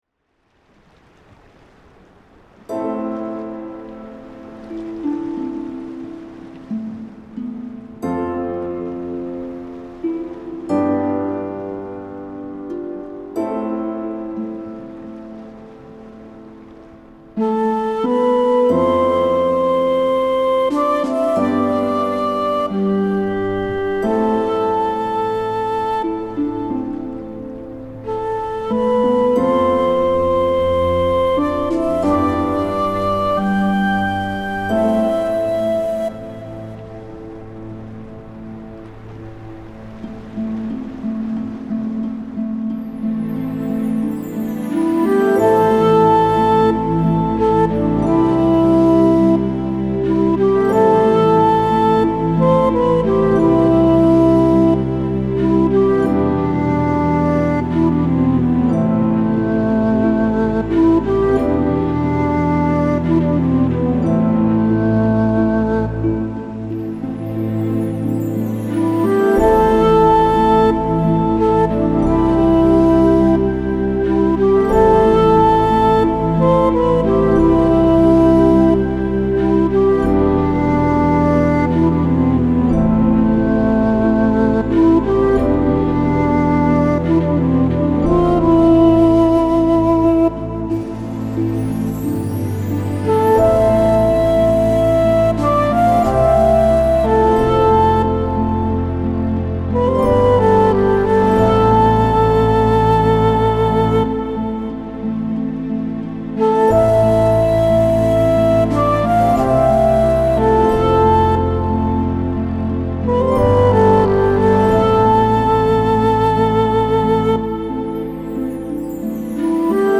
🌿 Wellness Musik für Unternehmen (Atmosphäre & Entspannung)
👉 Musik, die nicht auffällt –